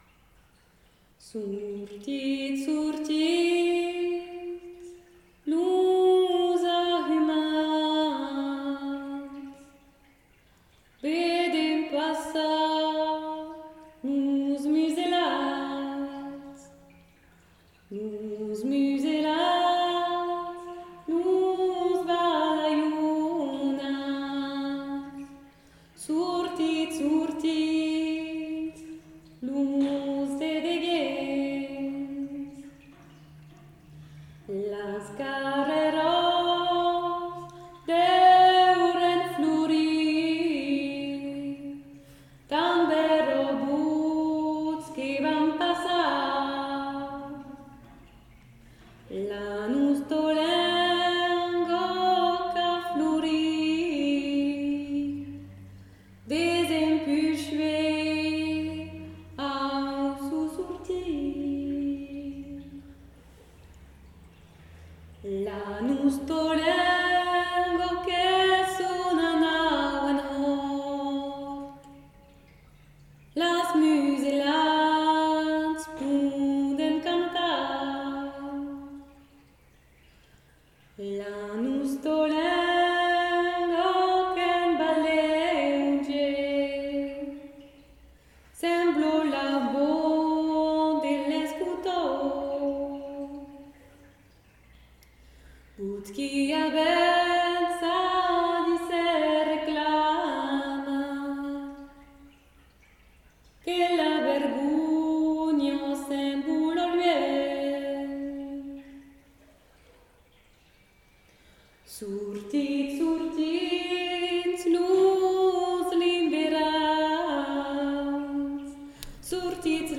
Dire, écouter, tendre – un silence, à voix haute, à deux voix ; tantôt textes originaux, tantôt corpus d’auteur.ices qui touchent notre sensibilité. Poïesis est une espace radiophonique dédié aux voix poétiques.